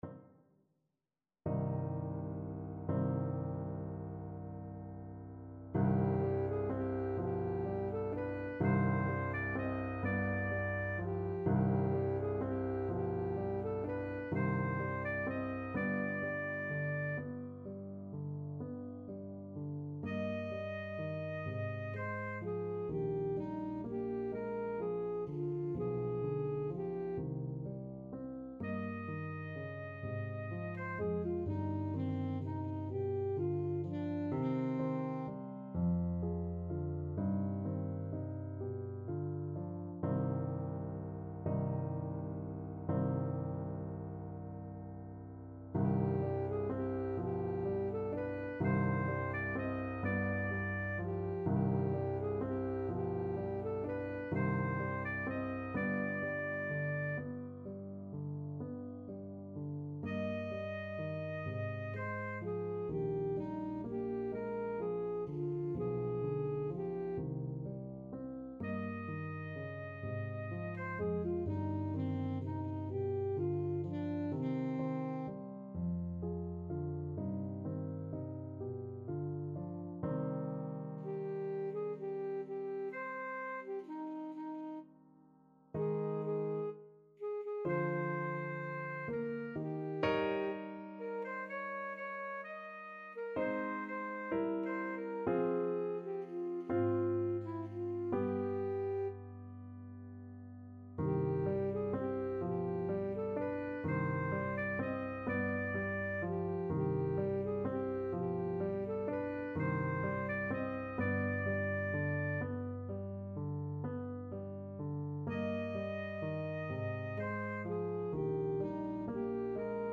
Classical Schubert, Franz Der Abend, D.108 Alto Saxophone version
Alto Saxophone
C minor (Sounding Pitch) A minor (Alto Saxophone in Eb) (View more C minor Music for Saxophone )
= 42 Andante con moto (View more music marked Andante con moto)
6/8 (View more 6/8 Music)
Classical (View more Classical Saxophone Music)